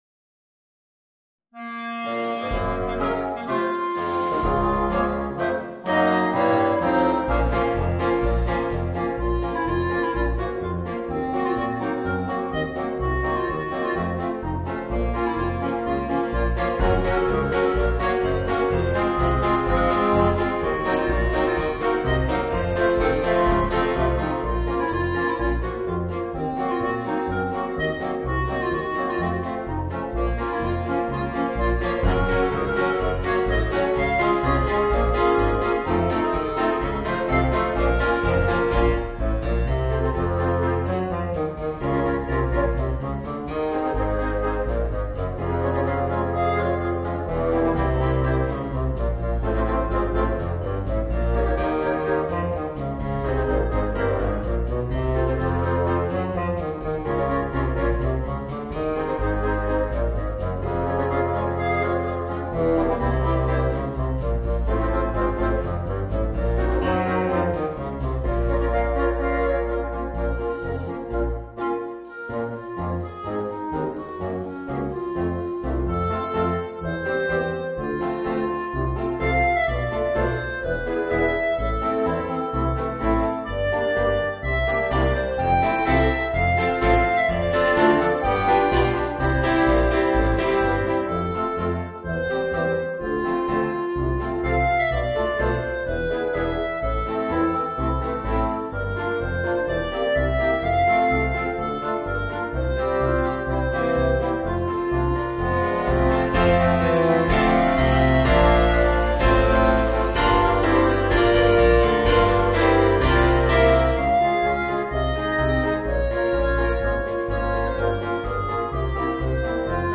Voicing: Woodwind Quintet and Rhythm Section